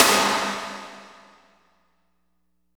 52.04 SNR.wav